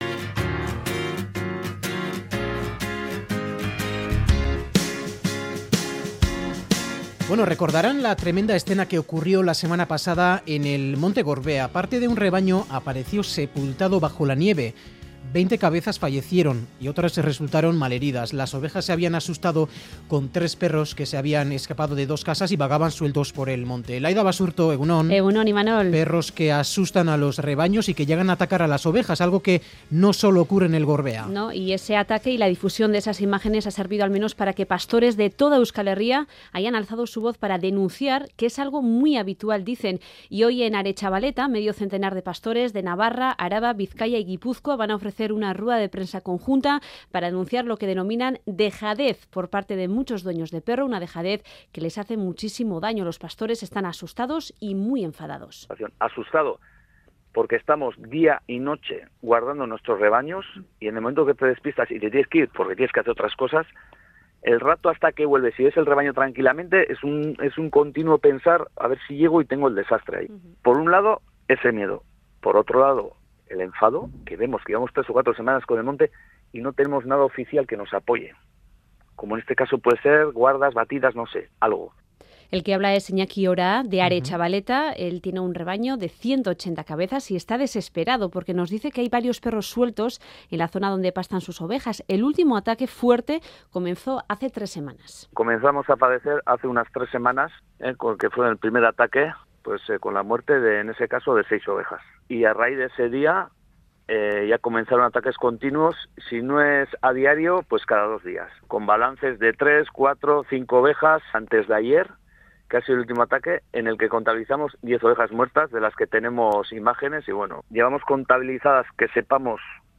Audio: Varios pastores relatan los ataques a ovejas que están teniendo lugar últimamente en los montes por parte de perros asilvestrados o por perros sueltos.